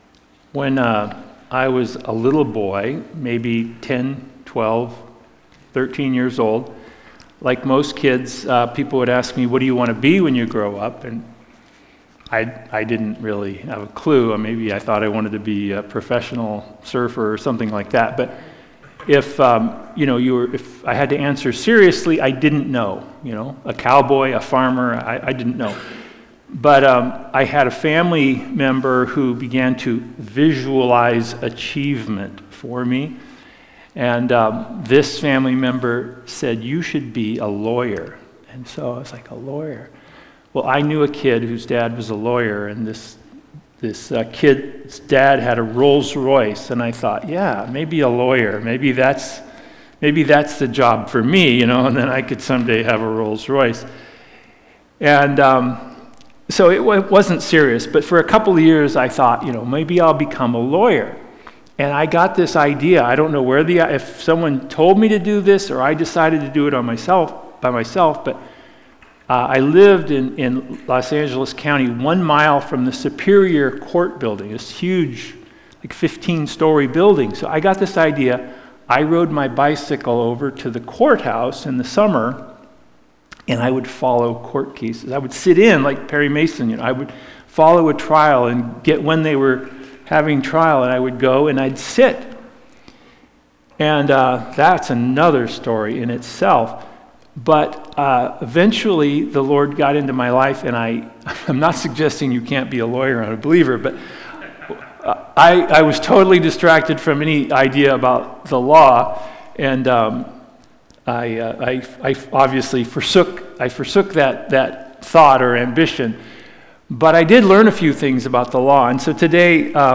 Sun-October-8-2023-sermon.mp3